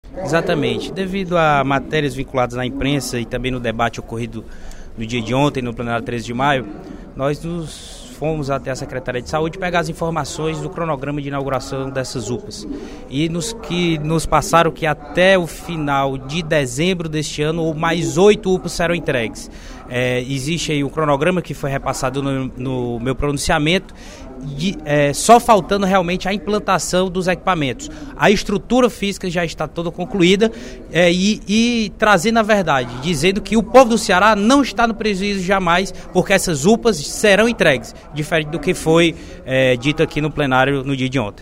O deputado Júlio César Filho (PTN), vice-líder do Governo, em pronunciamento nesta quarta-feira (23/10), durante o primeiro expediente da Assembleia Legislativa, afirmou que o Governo do Ceará é o que mais construiu Unidades de Pronto Atendimento (UPAs) em todo o Brasil.